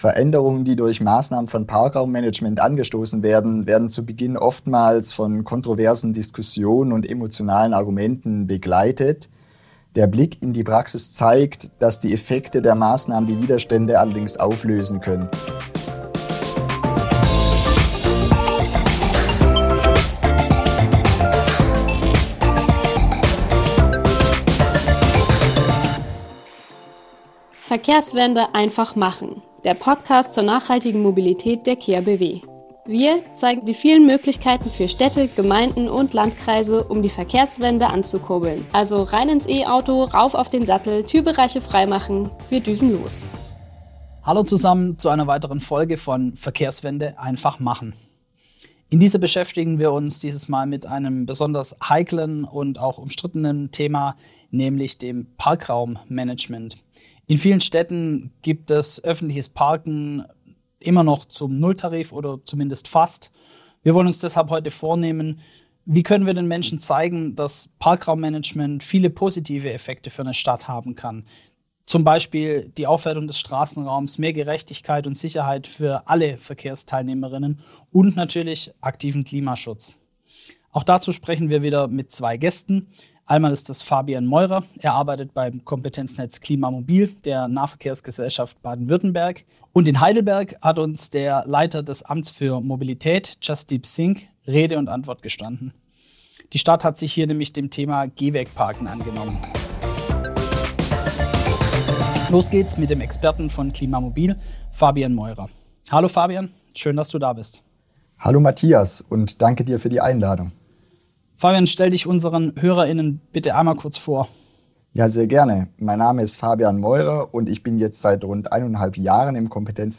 Wie man der Herausforderung begegnet, welche Lösungen und Hilfestellungen es im Land gibt – und wie Kommunen gut kommunizieren und argumentieren können – all das besprechen wir in dieser Folge. Zu Gast sind dazu: